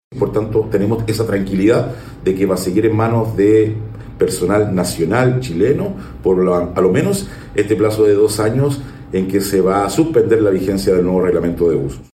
A su vez, el diputado Héctor Ulloa, dijo que está la tranquilidad de que el buceo seguirá en manos de personal nacional por al menos un plazo de 2 años.